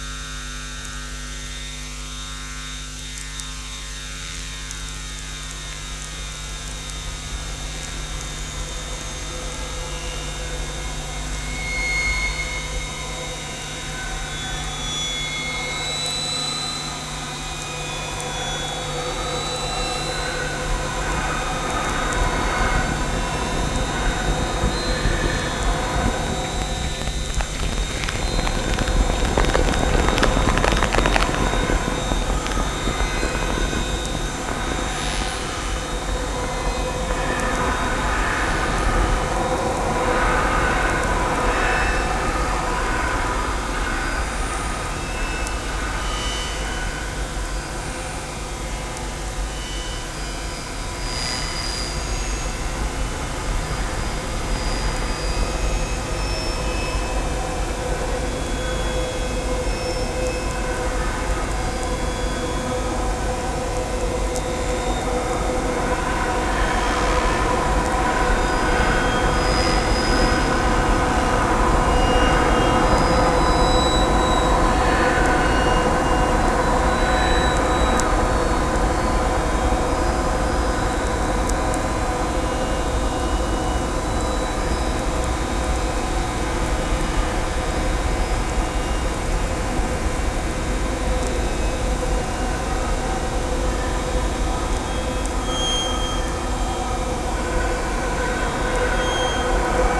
Field Recording Series